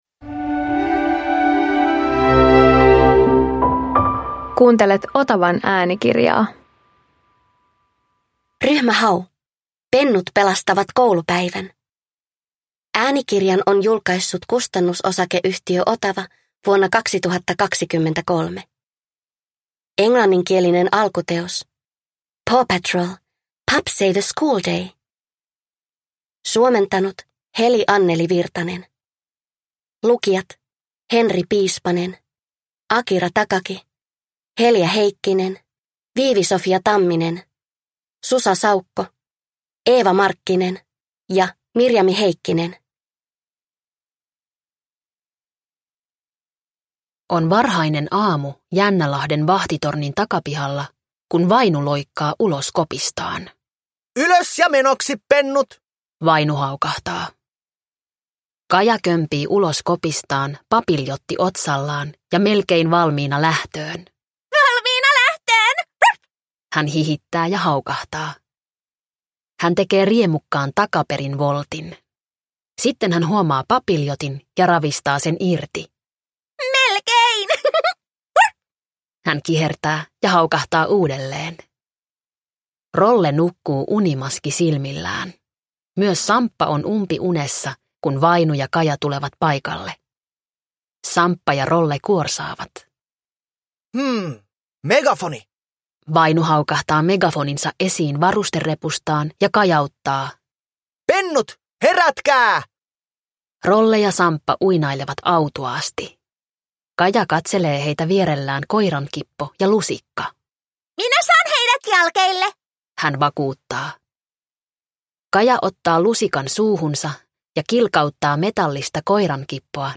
Ryhmä Hau - Pennut pelastavat koulupäivän – Ljudbok